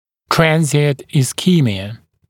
[‘trænzɪənt ɪsˈkiːmɪə][‘трэнзиэнт исˈки:миэ]переходящая ишемия